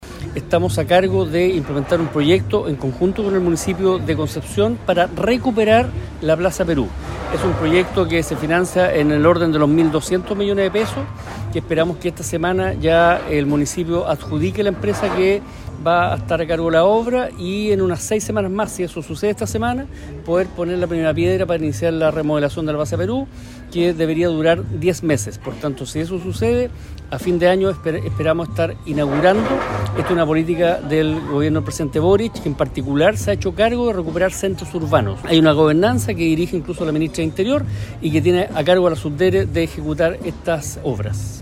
El jefe regional Biobío de la Subdere, Patricio Rojas, reforzó la labor que llevan a cabo junto con la Municipalidad de Concepción, para mejorar la Plaza Perú.